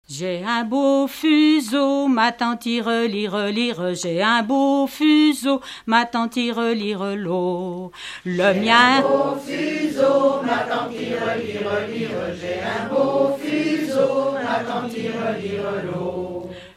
Enfantines - rondes et jeux
danse : ronde à se retourner
Pièce musicale inédite